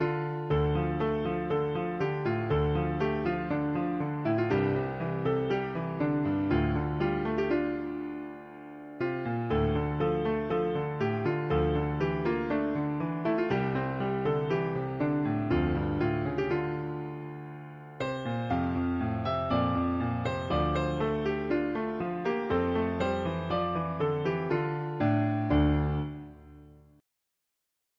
With thee all night I mea… english theist 1part accompanied